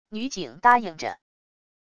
女警答应着wav音频